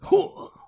Male Hit 3.wav